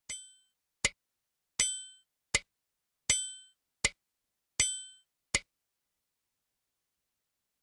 Her kan du høyre ein metronom:
Metronom med puls på 2
(Taktarten heiter då oftast 2/4)